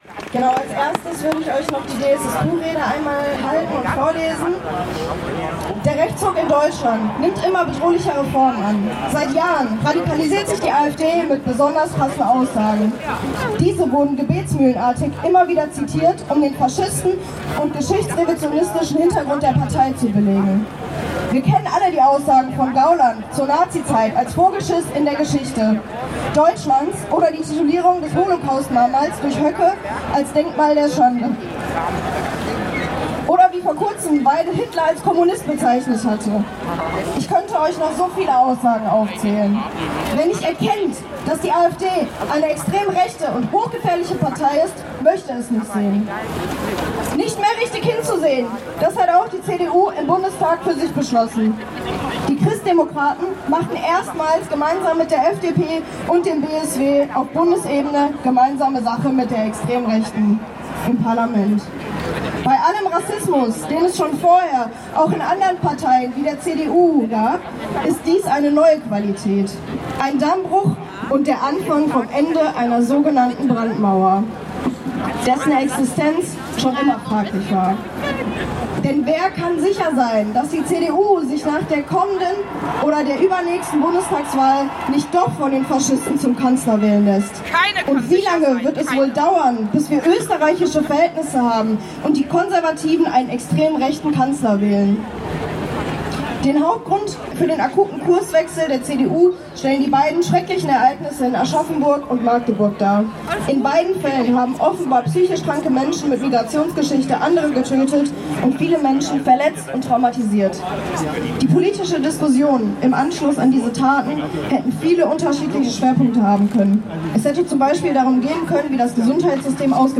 Die Verlesung der DSSQ-Rede
Düsseldorf stellt sich quer: Demonstration „Gegen die AfD und die Rechtsentwicklung der Gesellschaft“ (Audio 2/16)